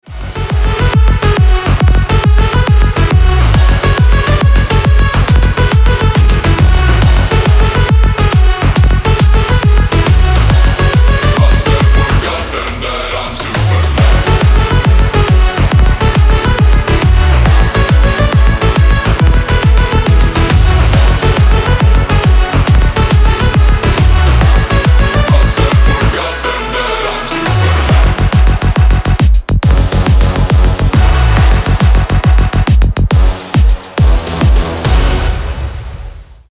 • Качество: 320, Stereo
мужской голос
громкие
EDM
энергичные
Big Room
Стиль: big room